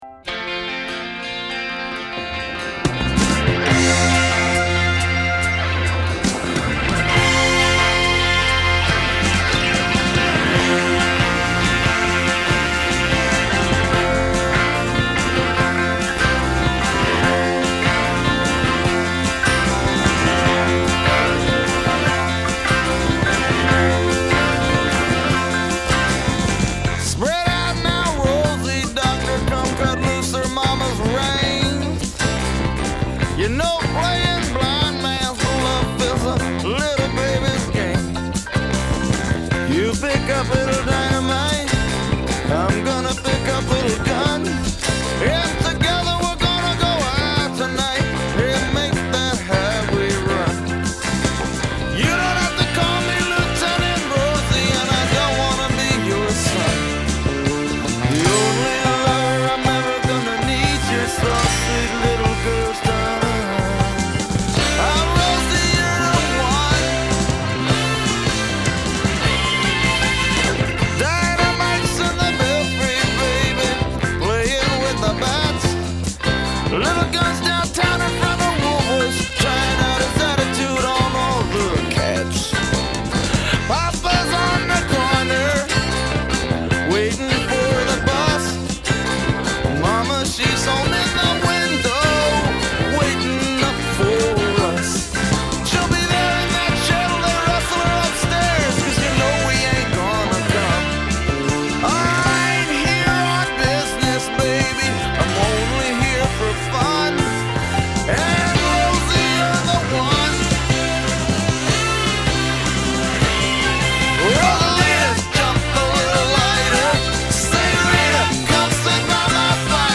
fierce romantic energy